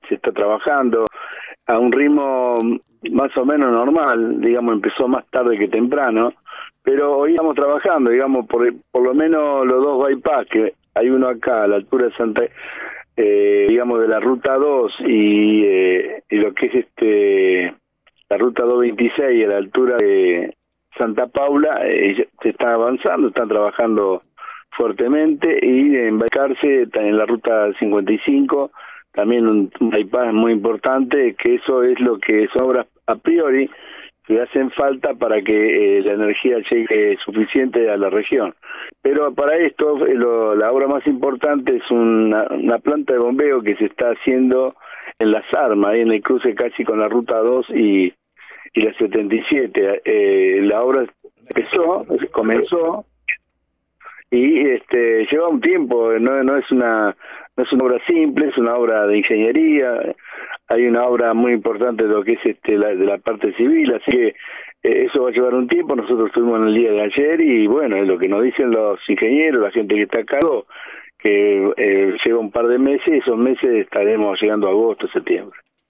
mostró su preocupación por la situación de la actividad en una entrevista en el  programa radial “Bien Despiertos”, emitido de lunes a viernes de 7 a 9 por De la Azotea 88.7.